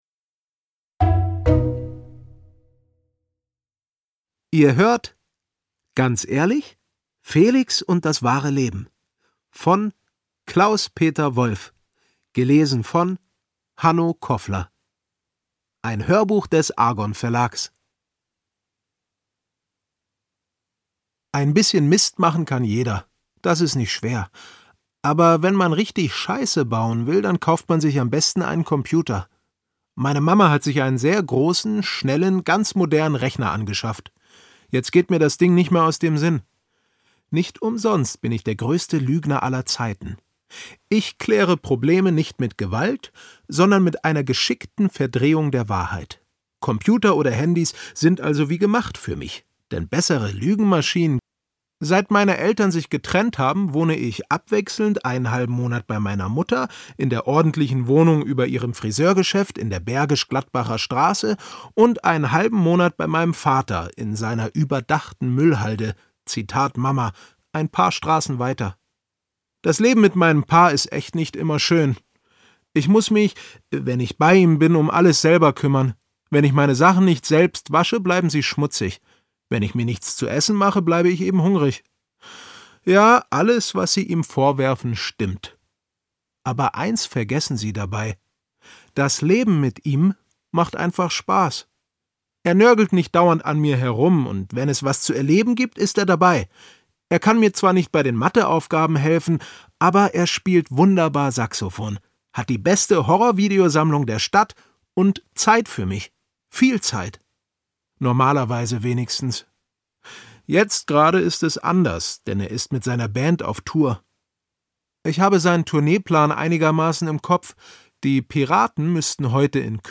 Ganz ehrlich? Felix und das wahre Leben Lustige Alltagsgeschichten ab 10 Jahren│Für alle Fans von Klaus-Peter Wolf Klaus-Peter Wolf (Autor) Hanno Koffler (Sprecher) Audio Disc 2024 | 1.